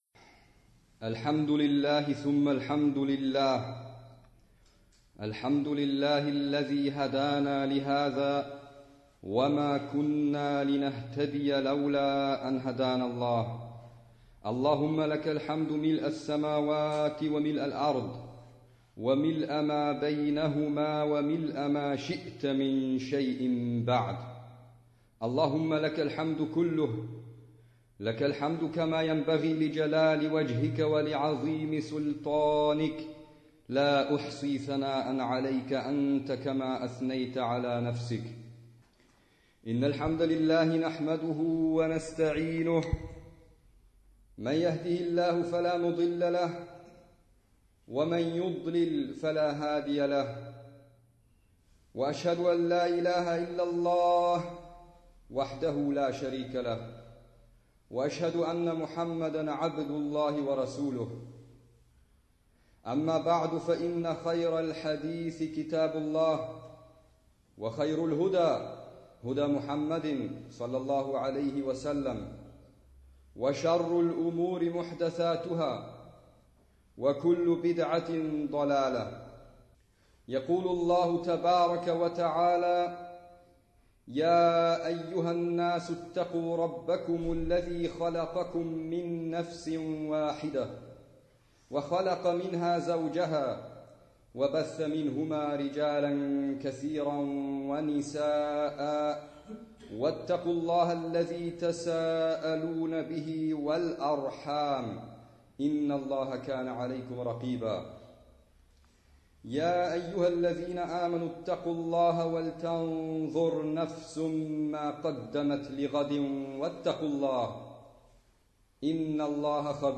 Freitagsansprache: Toleranz bei Meinungsunterschieden – Die schlechte Vermutung
Als Erleichterung für den deutschsprachigen und auch den nicht-muslimischen Zuhörer wurde es vorgezogen, eine gekürzte Fassung zur Verfügung zu stellen, bei der vor allem längere arabische Teile weggekürzt wurden. Die eigentliche Ansprache besteht aus zwei Teilen, dazwischen eine kurze Pause. Am Ende folgt das Gebet mit Rezitation von Versen aus dem Koran, welche meistens auch einen Bezug zum Thema haben oder in der Ansprache erwähnt werden. Die im deutschen Hauptteil der Ansprache zitierten arabischen Quelltexte unterbrechen den Redefluss nicht nennenswert und werden immer übersetzt.
Ursprünglicher Ort der Ansprache: al-Iman-Moschee in Wien